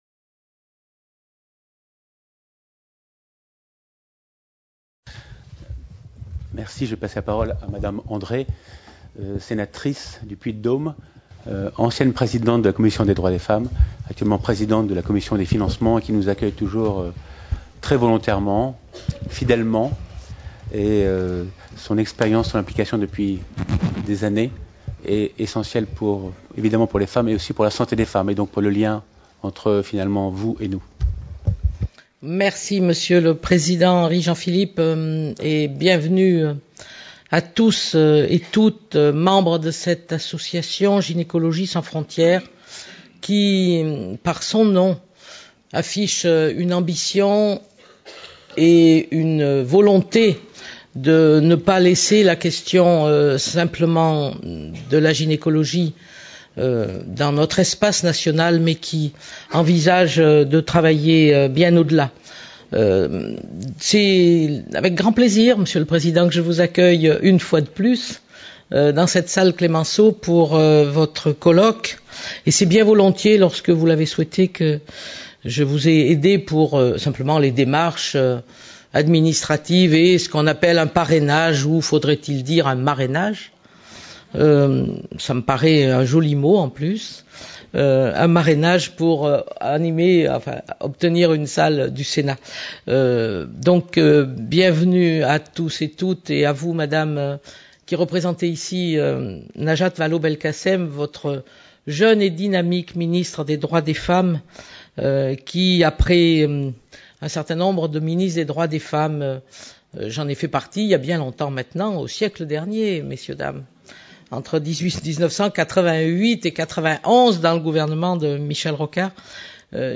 Discours de Mme Michèle André, Sénatrice du Puy-de-Dôme | Canal U
4ème Journée Humanitaire sur la Santé des Femmes Journée organisée par Gynécologie Sans Frontières, le 29 novembre 2013, au Palais du Luxembourg (Paris).